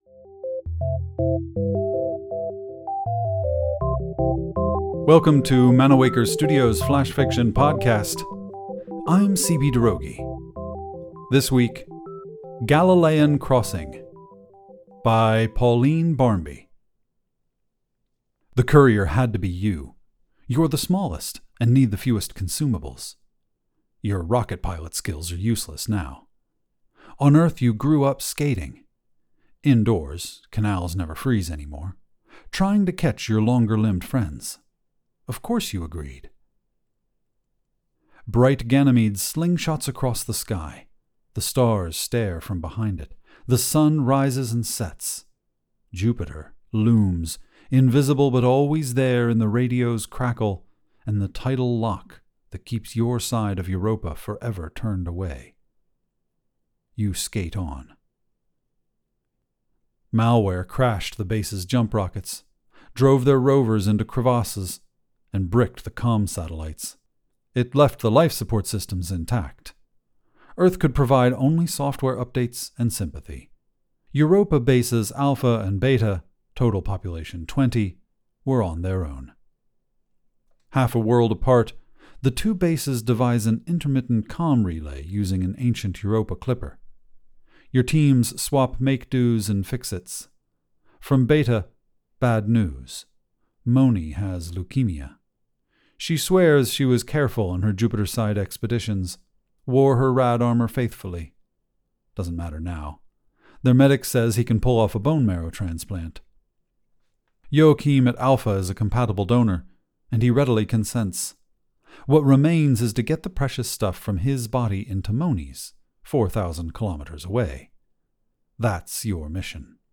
The Flash Fiction Podcast Theme Song is by Kevin McCleod